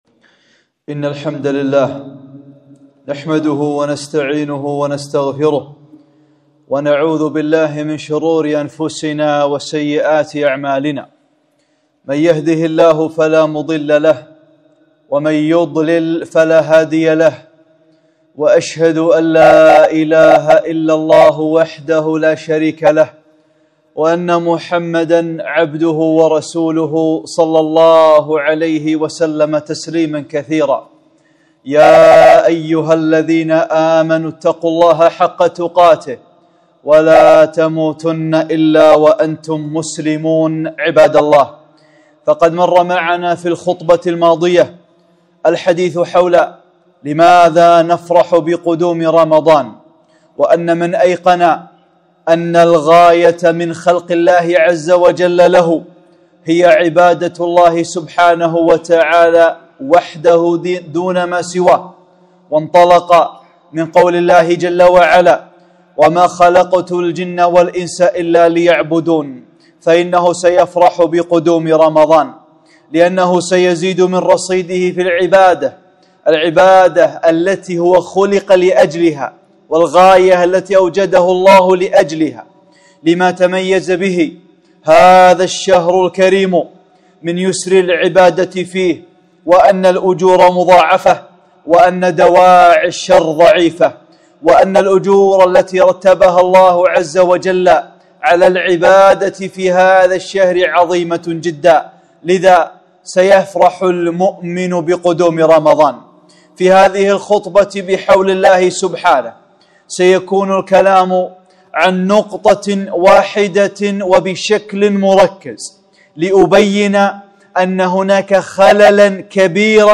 خطبة - لعلكم تتقون